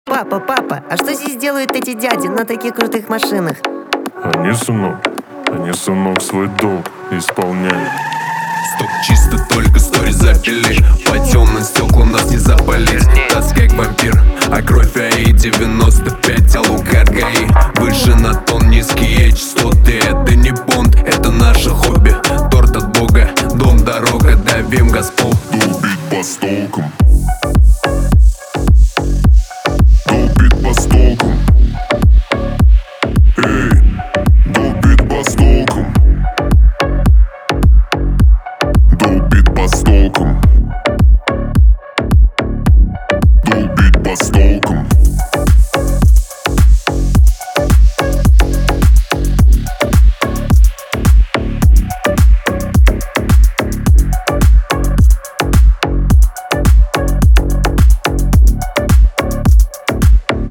• Качество: 320, Stereo
басы
G-House
цикличные